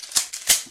shotgun.ogg